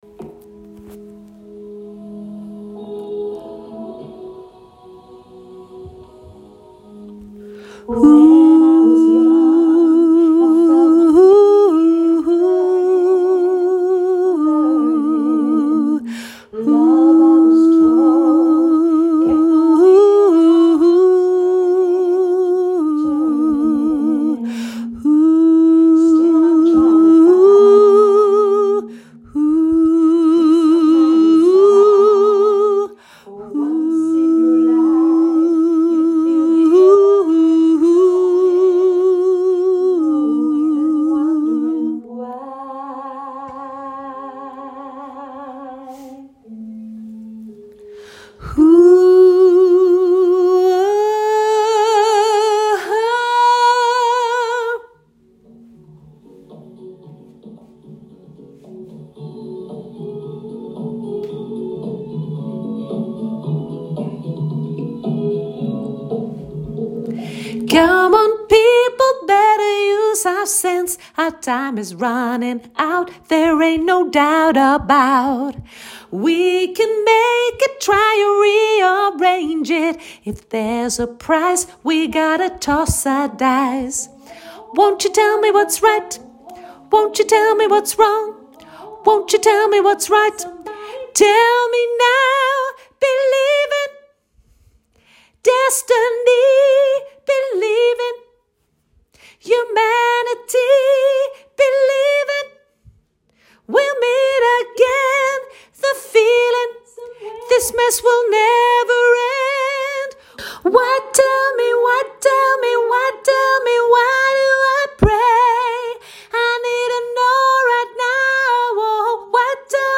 hoog sopraan